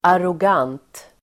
Ladda ner uttalet
arrogant adjektiv, arrogant Uttal: [arog'an:t] Böjningar: arrogant, arroganta Synonymer: högdragen, högmodig, mallig, överlägsen Definition: självsäker och nedlåtande (self-important and patronizing) Exempel: ett arrogant svar (an arrogant response)